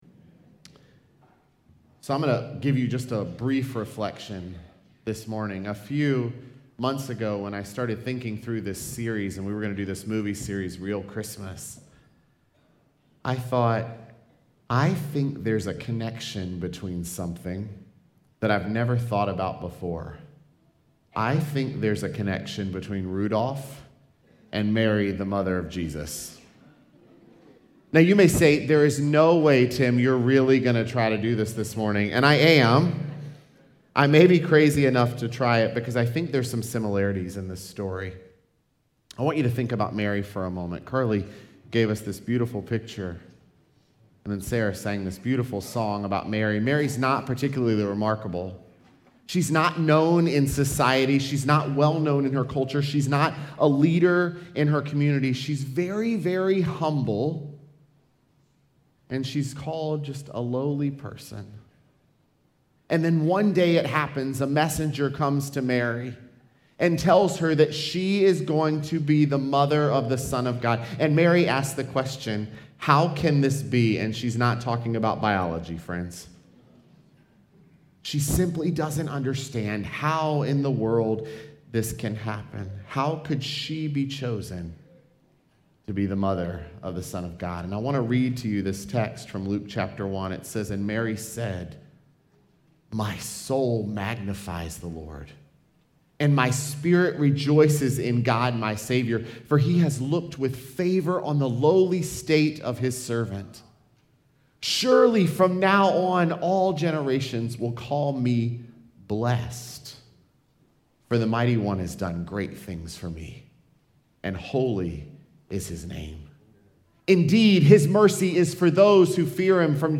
Dec17Sermon.mp3